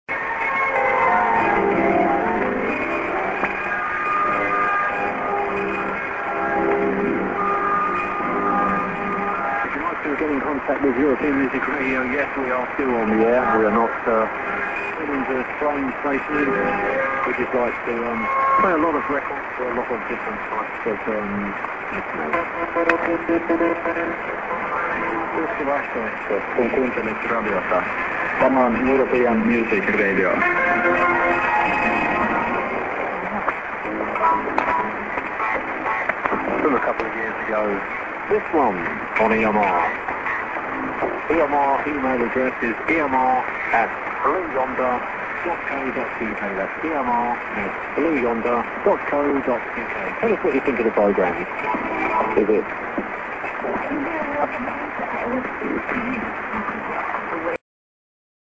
music->ID@00'30"->music